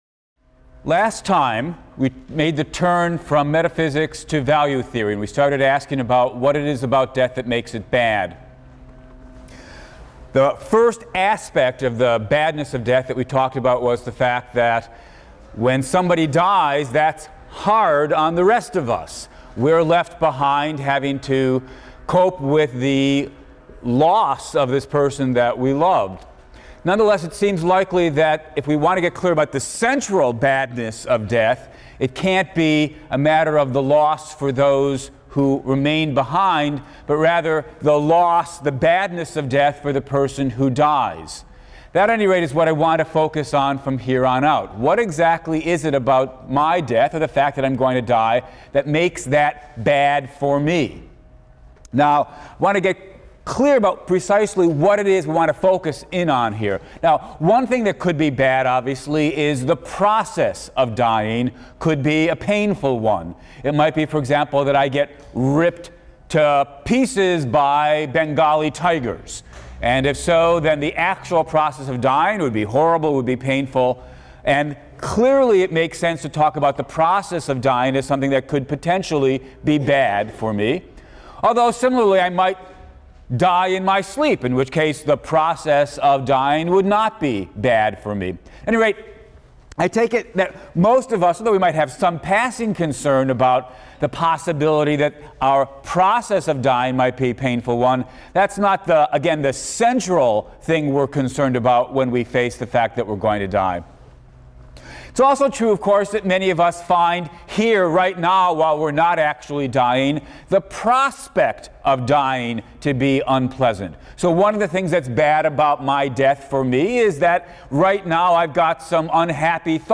PHIL 176 - Lecture 17 - The Badness of Death, Part II: The Deprivation Account | Open Yale Courses